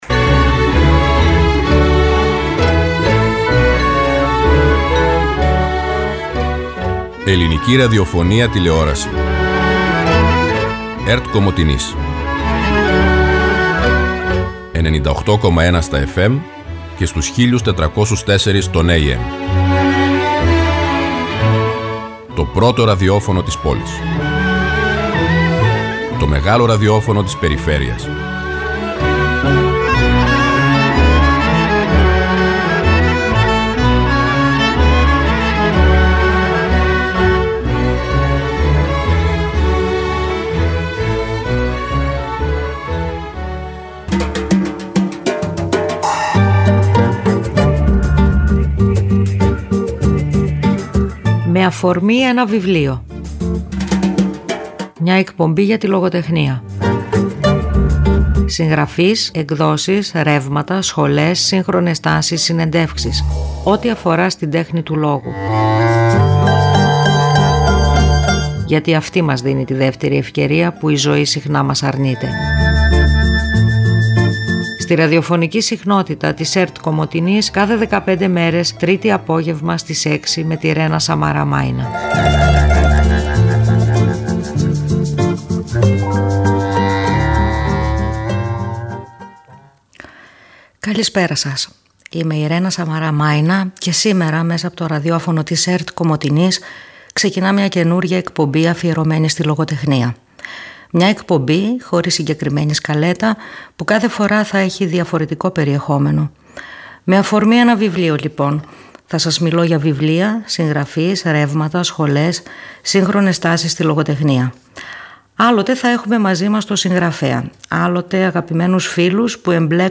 Μια εκπομπή για το βιβλίο και τη λογοτεχνία.